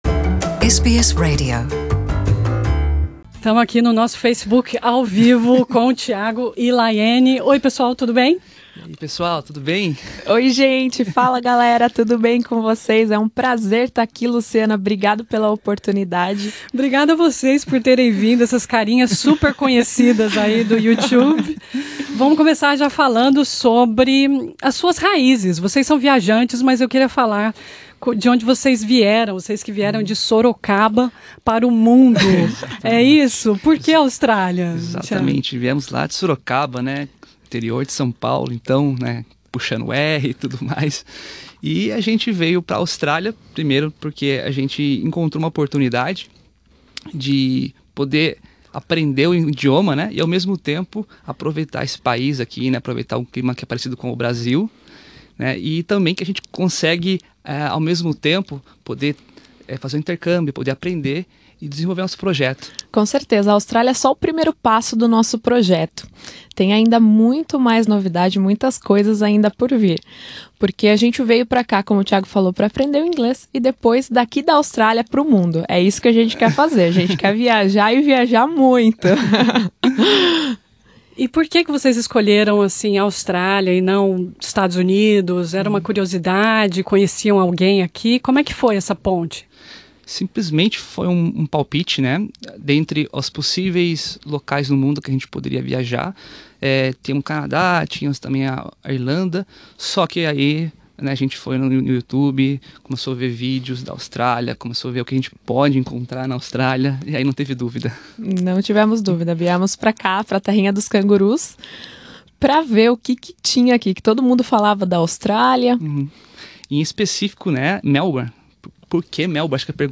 Nessa entrevista